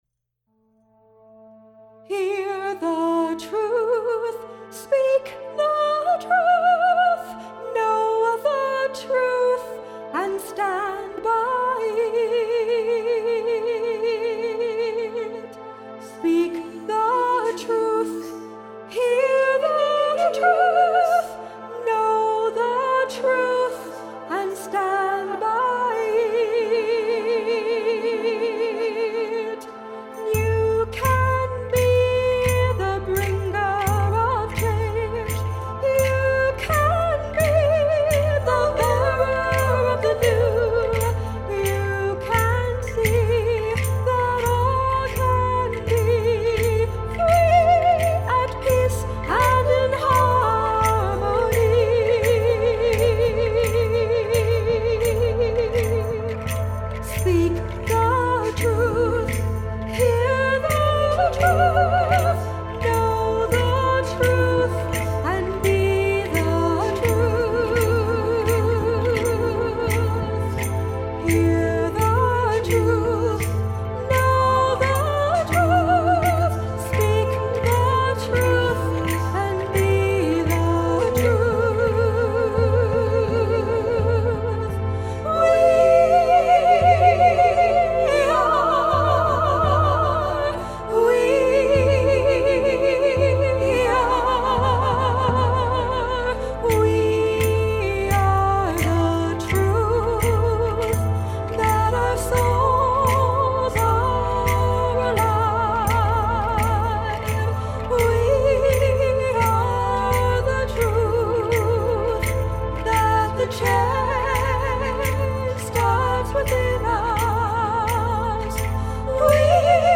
Drum
Rattle
Soundscapes